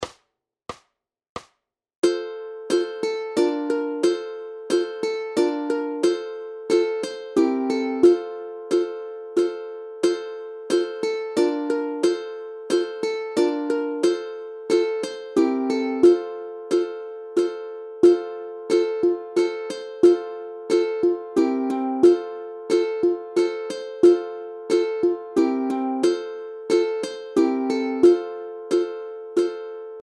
Aranžmá Noty, tabulatury na ukulele
Hudební žánr Vánoční písně, koledy